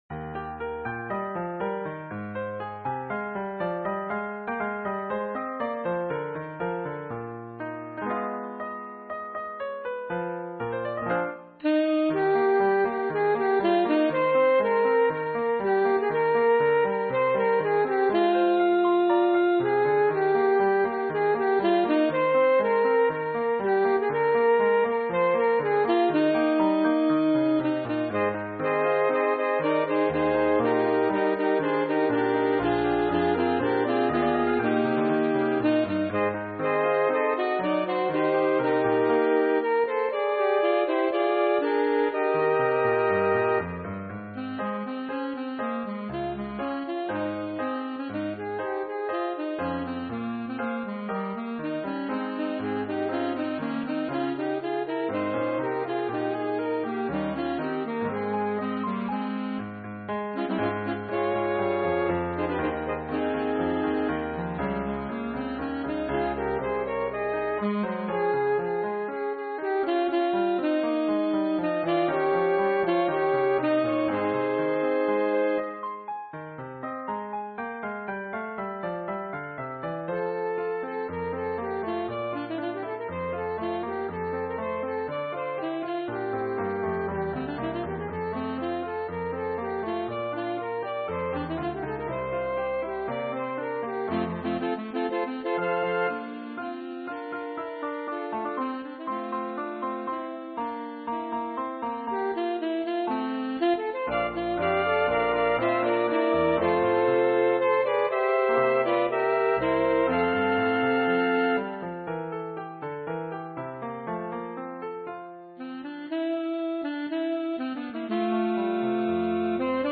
Eb, Ab, F
Trio: cl; sax
song (ternary)